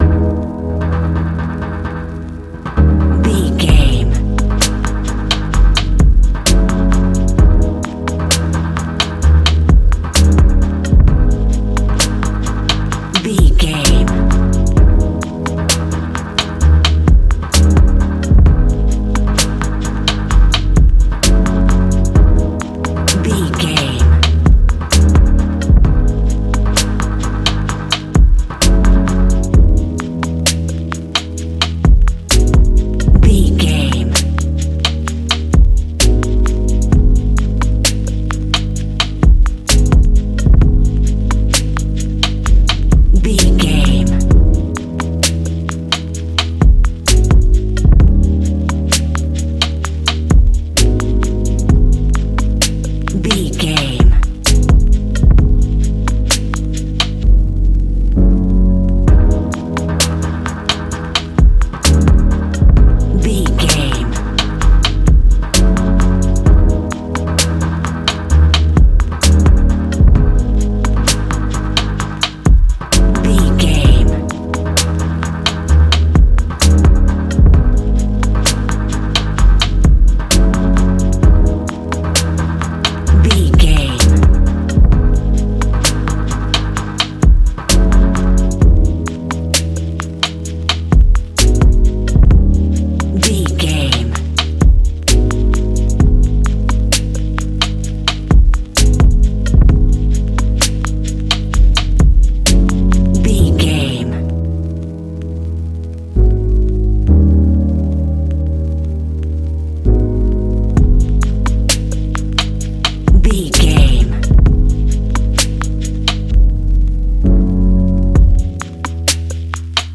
Ionian/Major
G♭
chilled
laid back
Lounge
sparse
new age
chilled electronica
ambient
atmospheric
morphing
instrumentals